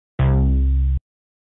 roblox spawn - Instant Sound Effect Button | Myinstants
roblox-spawn.mp3